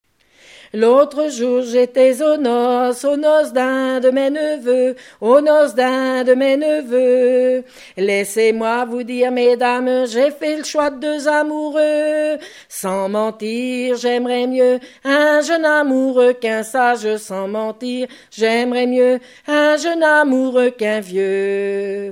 Chansons en dansant
Pièce musicale inédite